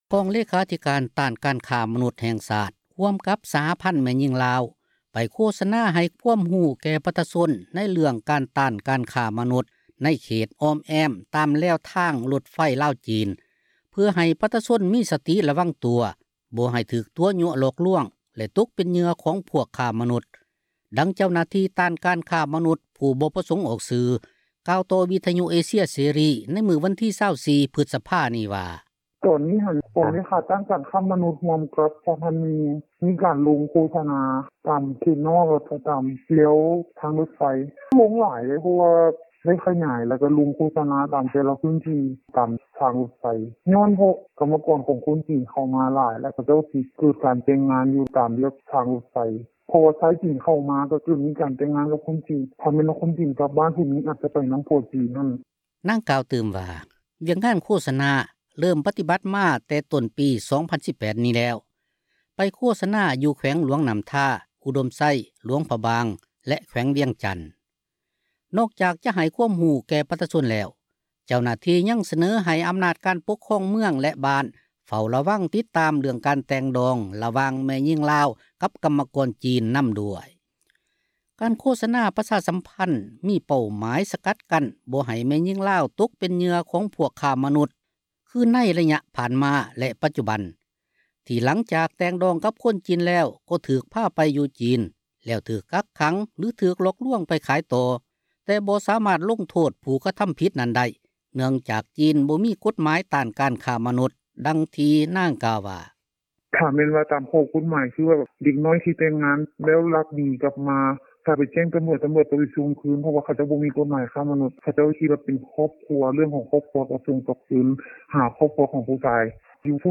ກອງເລຂາທິການ ຕ້ານການຄ້າມະນຸດ ແຫ່ງຊາດ ຮ່ວມກັບ ສະຫະພັນແມ່ຍິງລາວ ໄປໂຄສະນາ ໃຫ້ຄວາມຮູ້ ແກ່ປະຊາຊົນ ເຣື່ອງຕ້ານການຄ້າມະນຸດ ໃນເຂດອອ້ມແອ້ມ ຕາມແລວທາງຣົດໄຟ ລາວ-ຈີນ ເພື່ອໃຫ້ປະຊາຊົນ ມີສະຕິຣະວັງຕົວ, ບໍ່ໃຫ້ຖືກຕົວະຍົວະຫລອກລວງ ແລະ ຕົກເປັນເຫຍື່ອ ຂອງພວກຄ້າມະນຸດ, ດັ່ງເຈົ້າໜ້າທີ່ ຕ້ານການຄ້າມະນຸດ ຜູ້ບໍ່ປະສົງອອກສື່ ກ່າວຕໍ່ວິທຍຸ ເອເຊັຽ ເສຣີ ໃນມື້ວັນທີ 24 ພຶສພາ ນີ້ວ່າ: